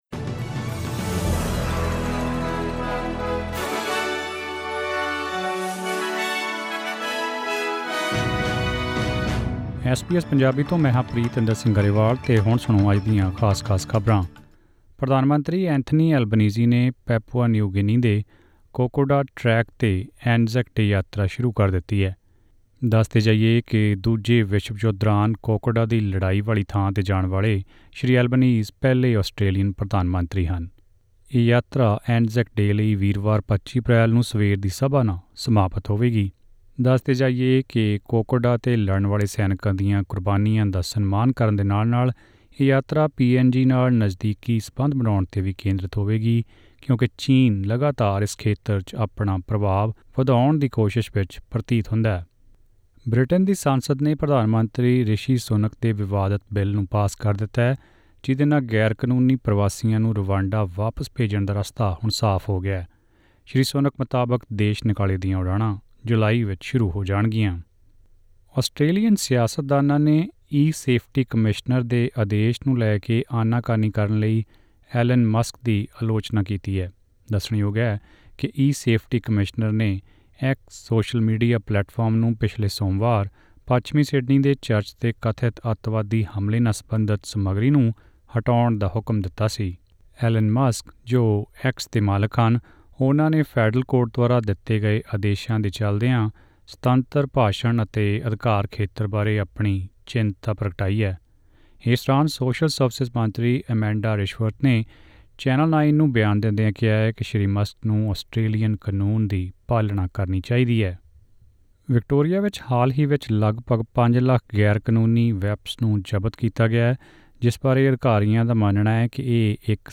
ਐਸ ਬੀ ਐਸ ਪੰਜਾਬੀ ਤੋਂ ਆਸਟ੍ਰੇਲੀਆ ਦੀਆਂ ਮੁੱਖ ਖ਼ਬਰਾਂ: 23 ਅਪ੍ਰੈਲ, 2024